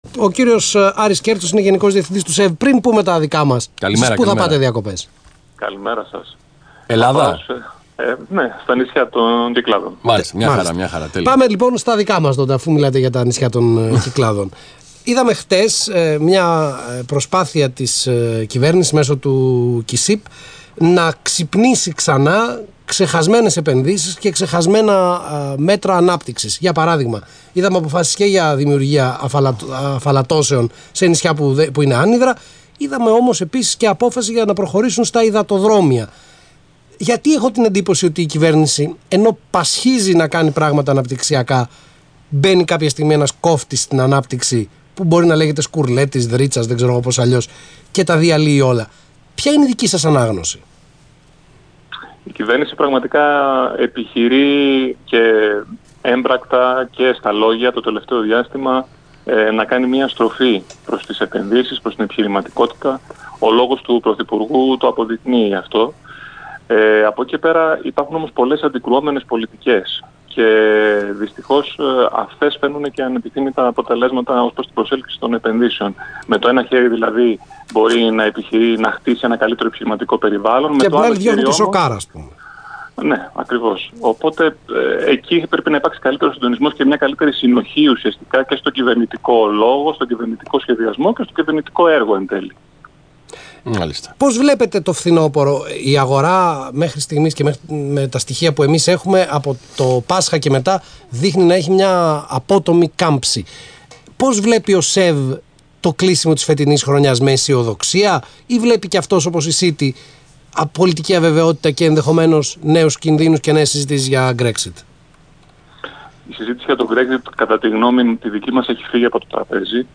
Ο Γενικός Διευθυντής του ΣΕΒ, κ. Άκης Σκέρτσος στον Ρ/Σ Αθήνα 9.84, 29/7/2016